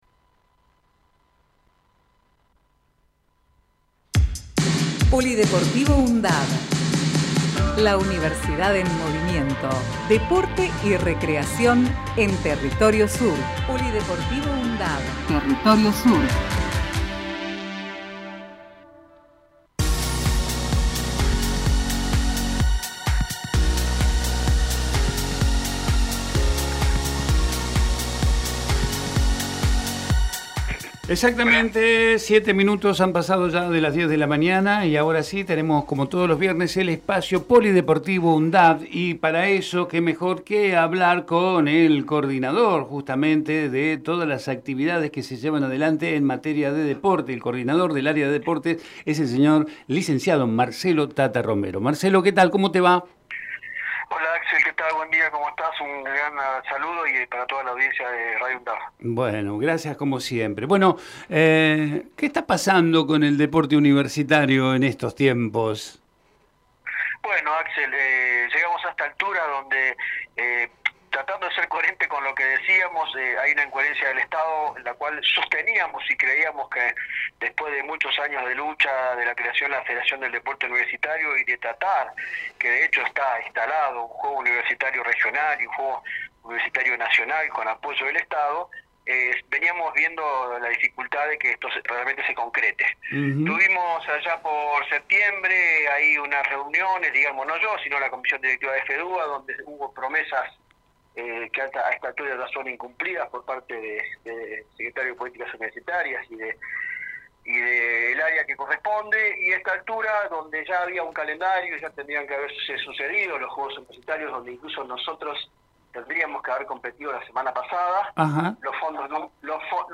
Compartimos entrevista en "Territorio Sur"